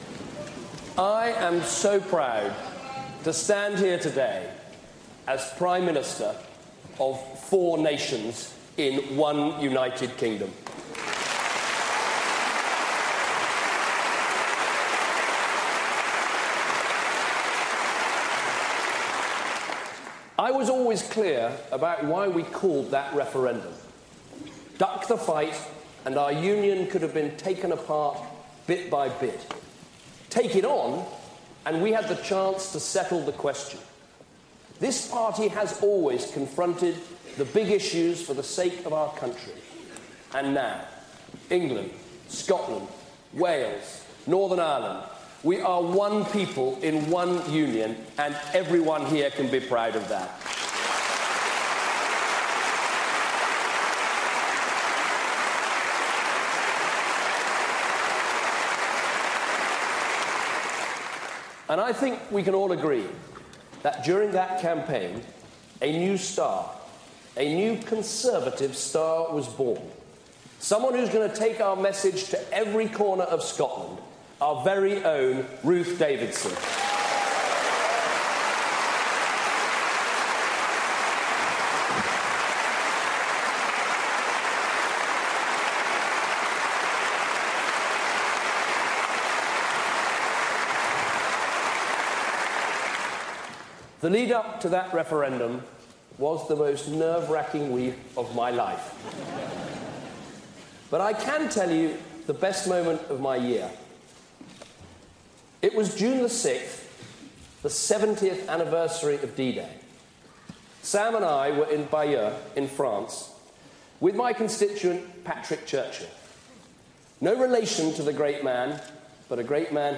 David Cameron's speech to the Conservative conference